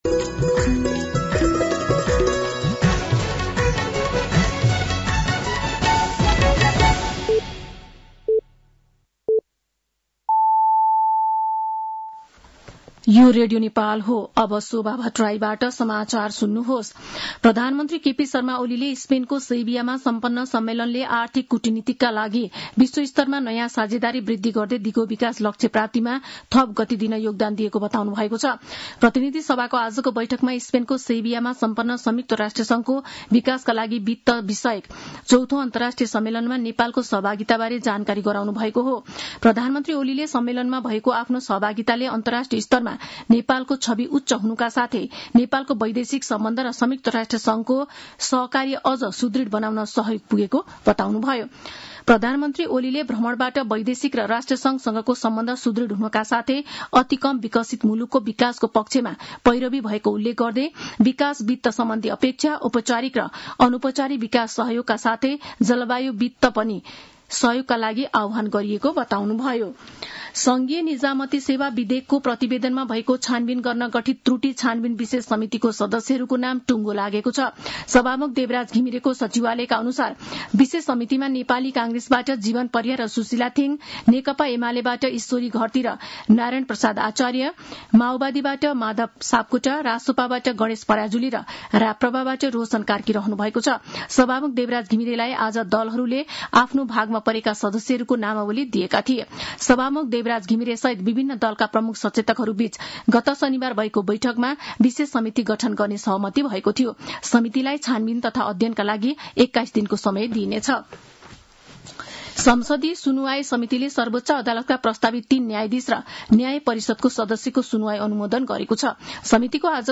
साँझ ५ बजेको नेपाली समाचार : २३ असार , २०८२
5.-pm-nepali-news-1-3.mp3